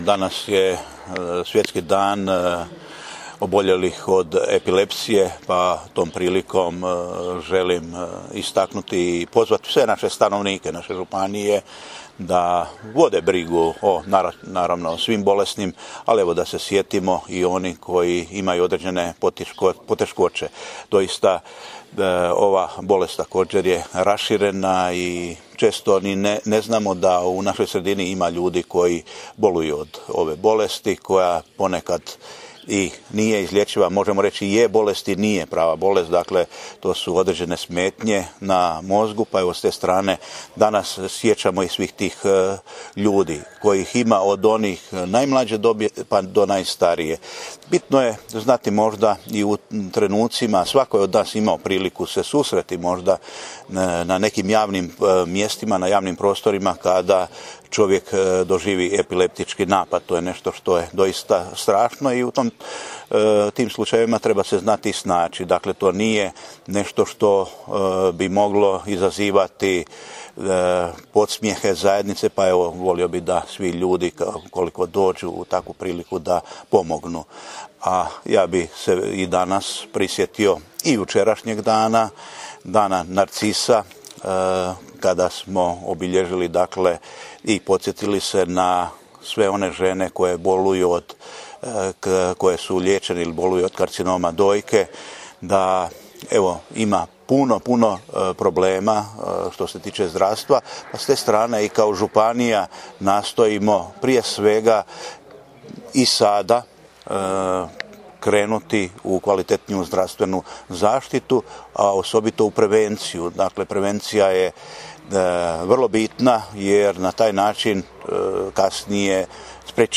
Izjavu, u kojoj je župan komentirao ovaj dan, ali i odnos prema zdravstvu i napore koje županije pod njegovim vodstvom ulaže u tom području, poslušajte ovdje: